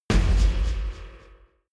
Royalty free music elements: Percussion
Royalty free Percussion for your projects.